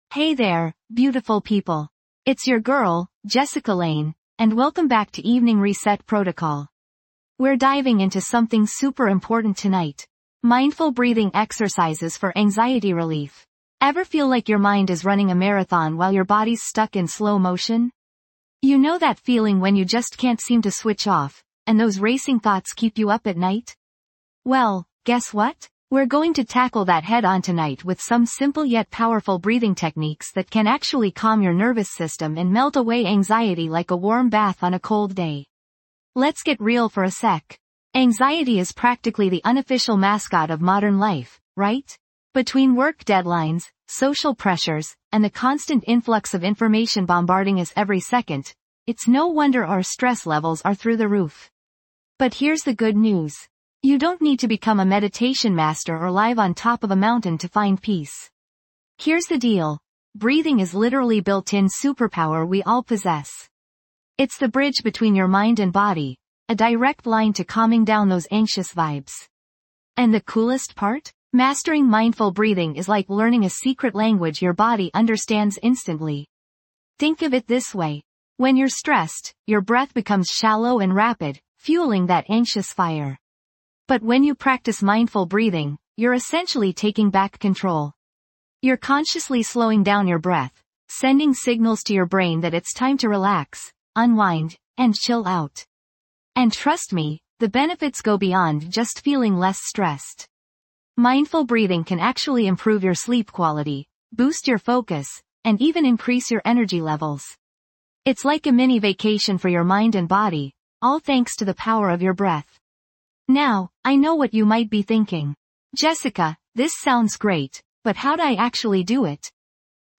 Dive into a serene episode of Evening Reset Protocol as we guide you through powerful mindful breathing exercises designed to alleviate anxiety. Learn effective techniques to promote calmness, enhance focus, and instill tranquility in your daily life.